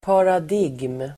Uttal: [parad'ig:m]